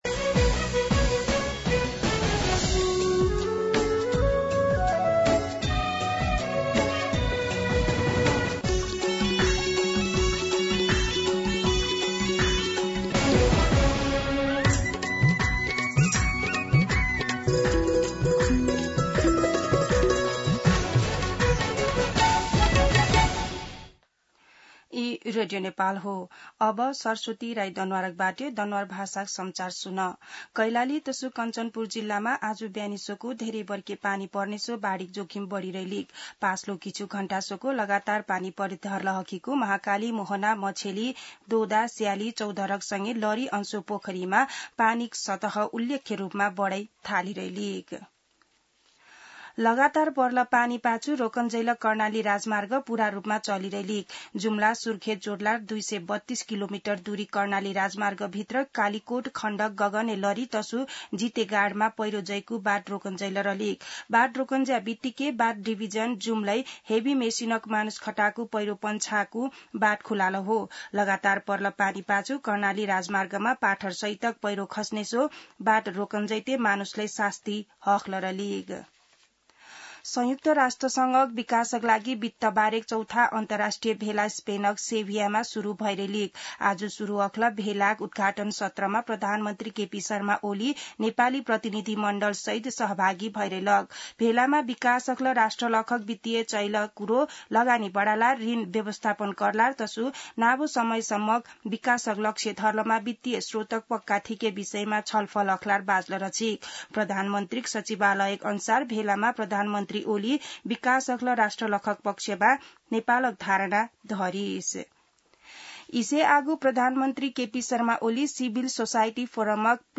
दनुवार भाषामा समाचार : १६ असार , २०८२